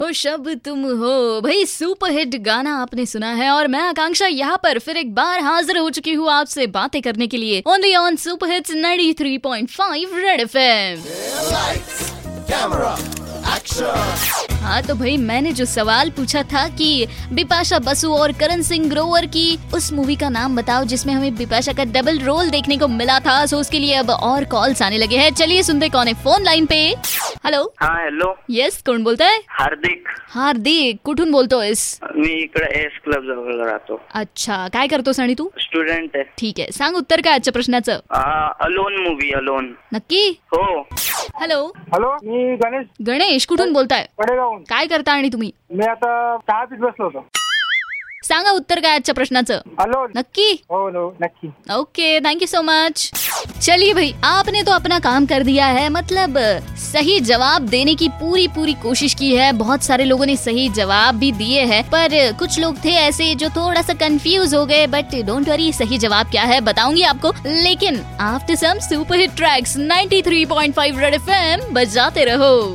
interacting with listeners